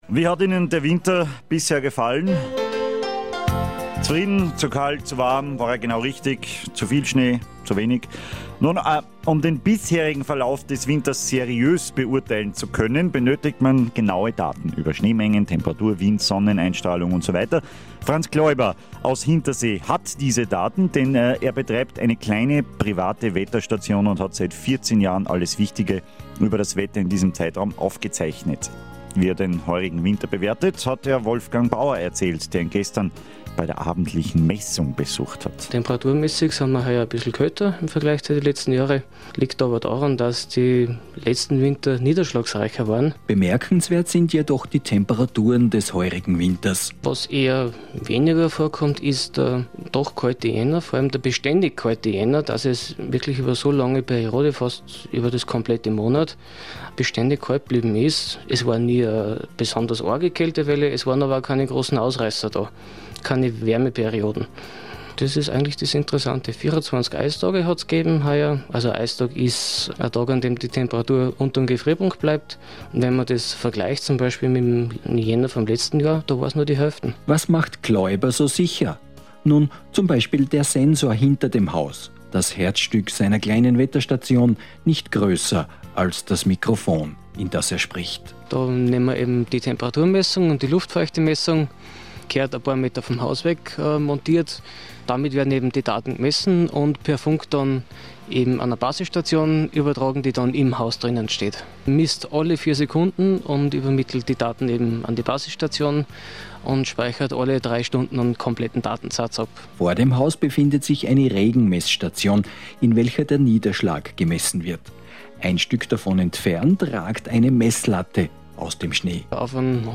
23.02.10 Interview ORF Radio Salzburg / Winter 09/10, Wetterstation